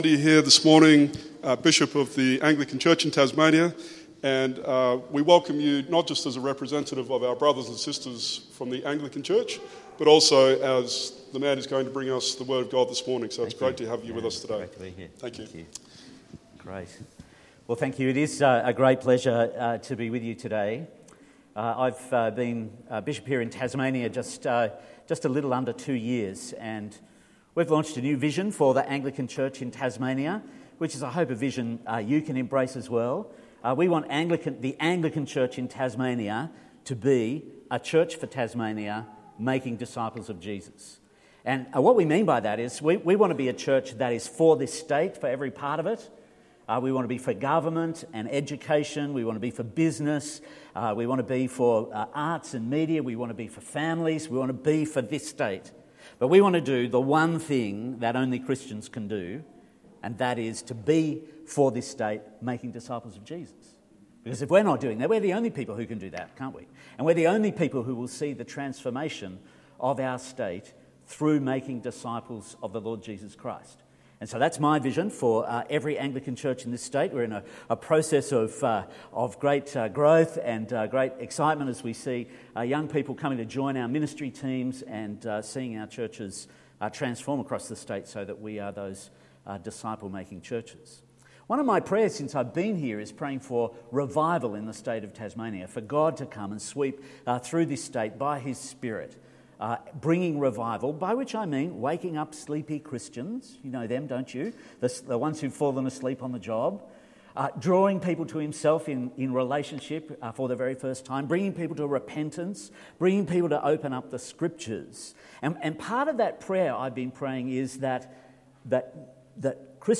Today we welcome Dr. Richard Condie, Anglican Bishop of Tasmania. Hw will prach to us today from Ephesians 2:11-22 .